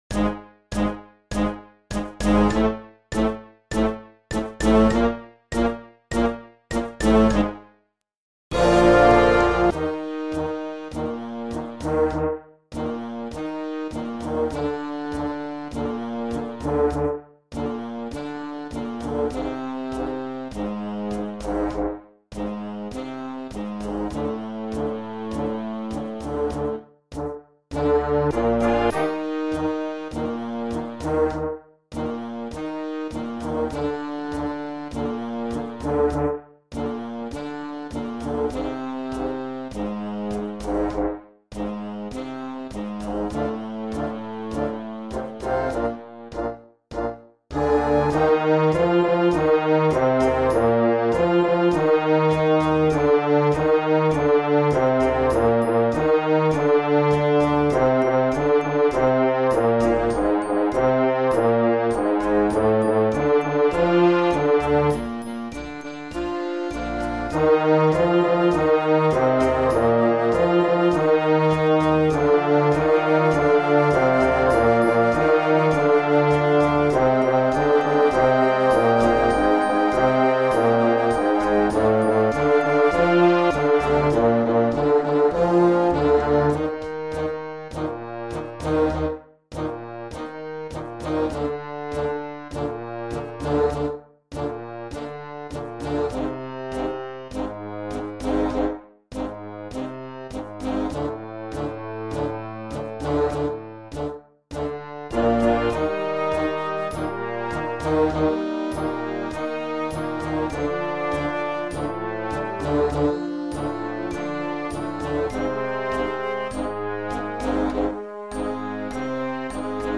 23M1 Young Concert Band $55.00
Computer MP3 sound file
Based on Argentine Tango.